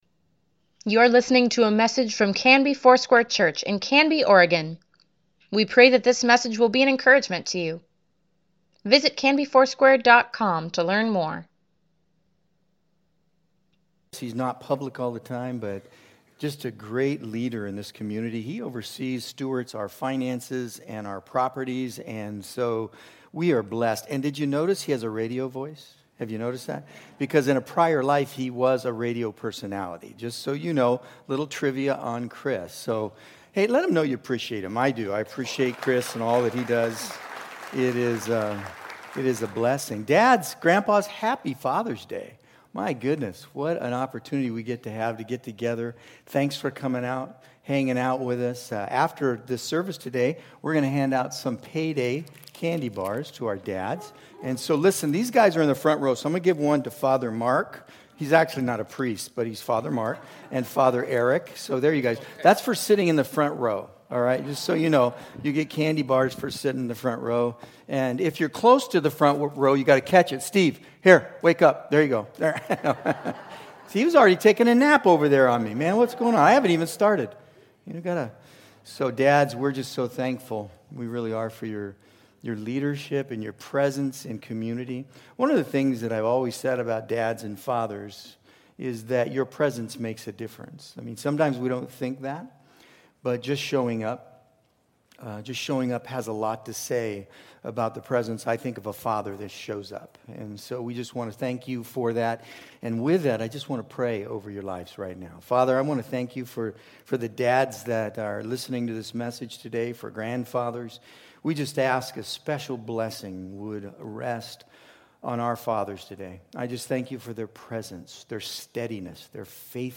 Weekly Email Water Baptism Prayer Events Sermons Give Care for Carus Jesus for Everyone, pt.22 June 20, 2021